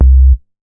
MoogCat 002.WAV